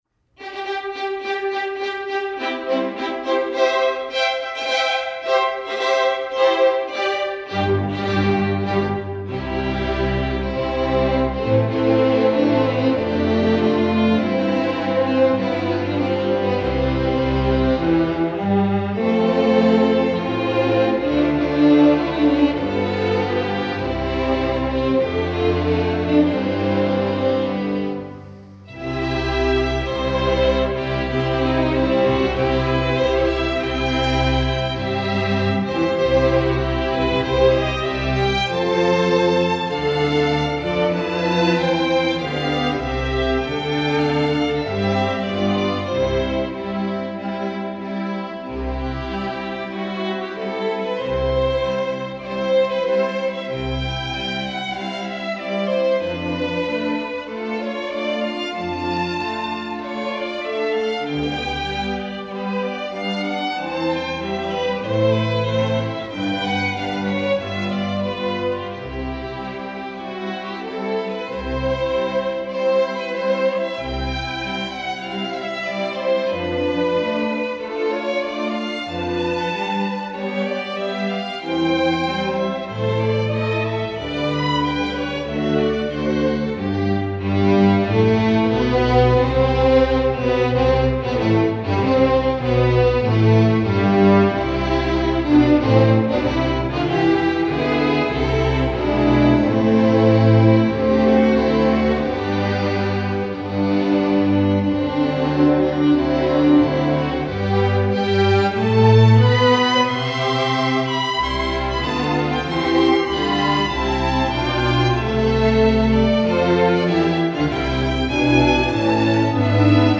classical, opera